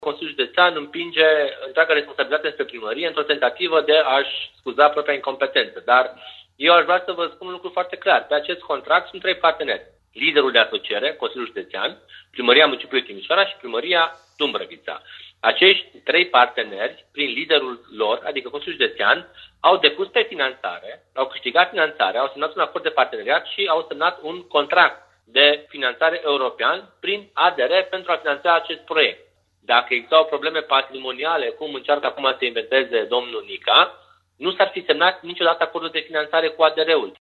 Viceprimarul Timișoarei, Ruben Lațcău, susține însă că stâlpii au fost predați Consiliului Județean și nimeni nu împiedică instituția să termine proiectul.
Ruben-Latcau-stalpi-troleu.mp3